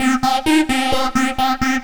Plucked Psytrance Arp
plucked-psytrance-arppegio_130bpm_C_minor.wav